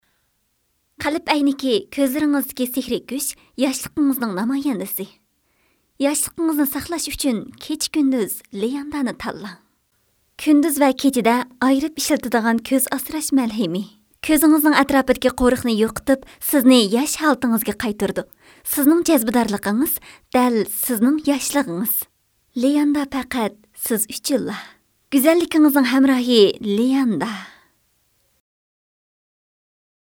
商业广告